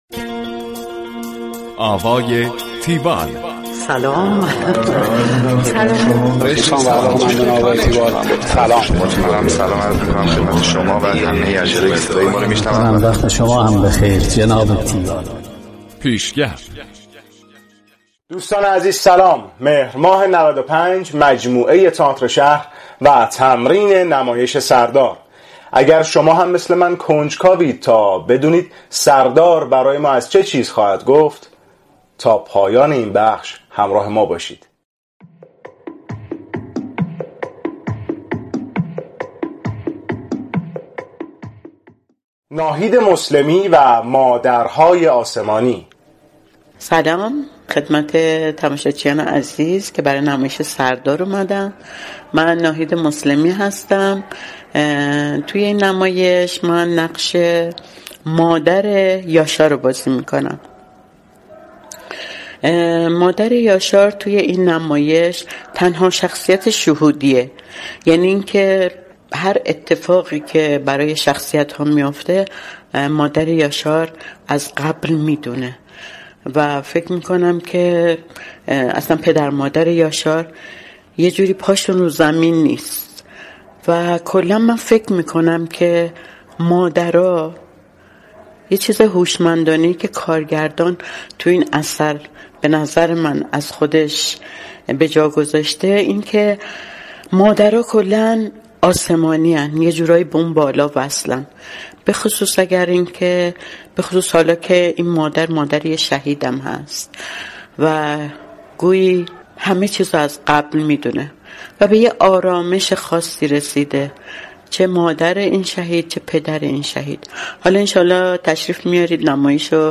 گزارش آوای تیوال از نمایش سردار
tiwall-report-sardar.mp3